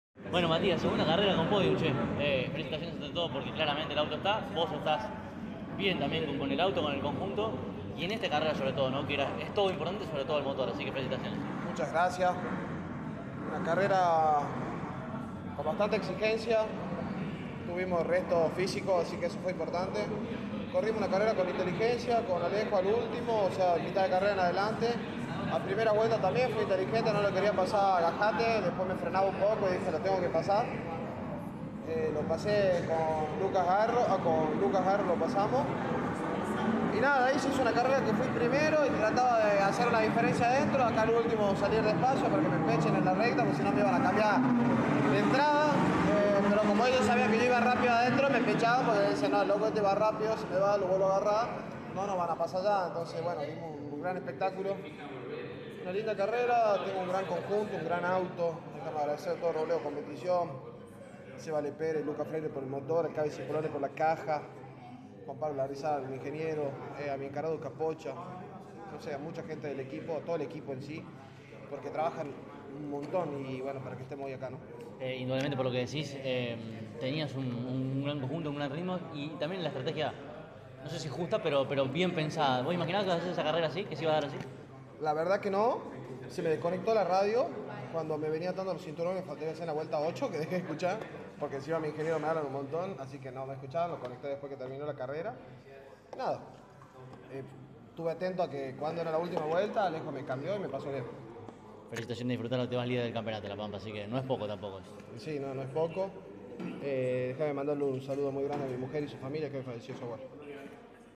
Todos ellos dialogaron con CÓRDOBA COMPETICIÓN, y aquí debajo, en orden, podés oir sus voces: